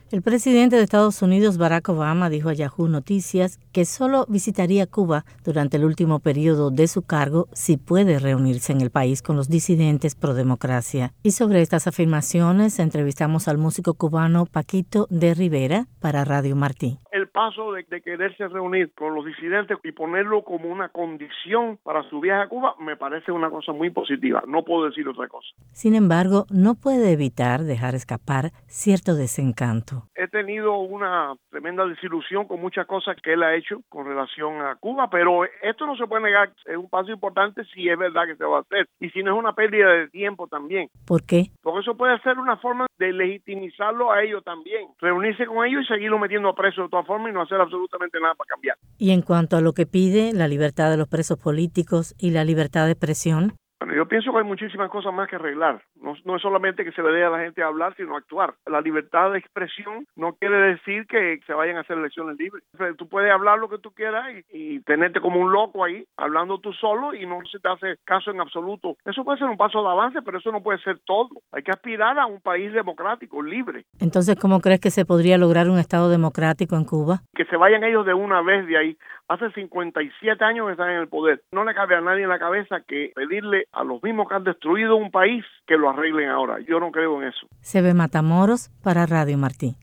Paquito D’Rivera, saxofonista cubano con numerosas nominaciones a los premios Grammy dijo estar de acuerdo con las afirmaciones de Obama.